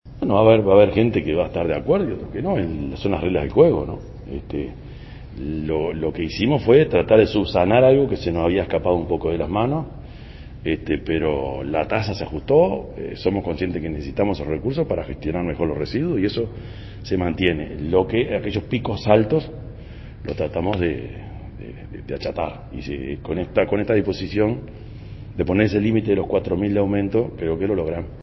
En la tarde de este jueves el intendente de Canelones, Yamandú Orsi, se refirió a las críticas de la oposición luego de anunciar el pasado martes que se resolvió “topear” la Tasa de Servicios del departamento a $4.000 pesos anuales.